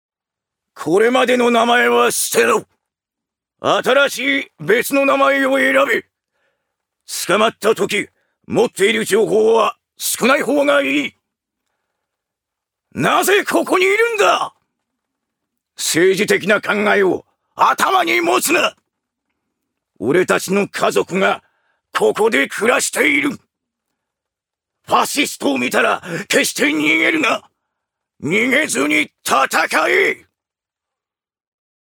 ジュニア：男性
セリフ３